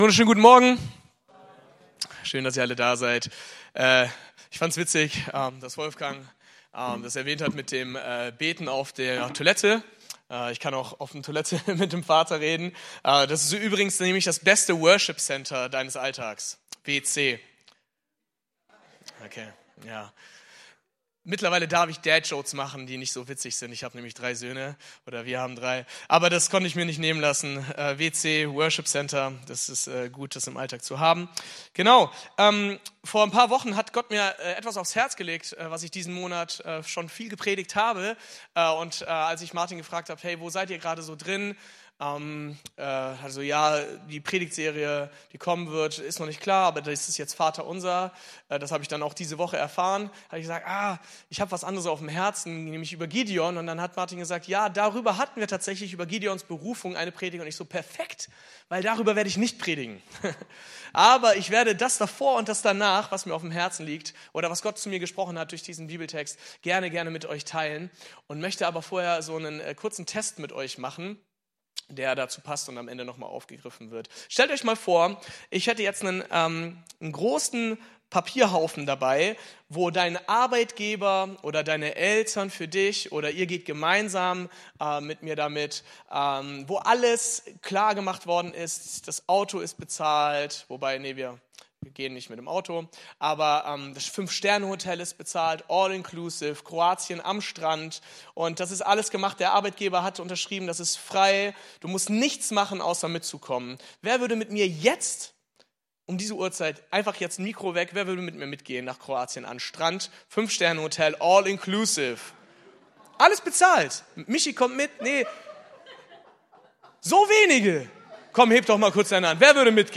Predigt-Details - FCG Ecclesia Laupheim